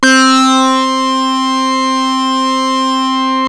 SYNTH LEADS-2 0001.wav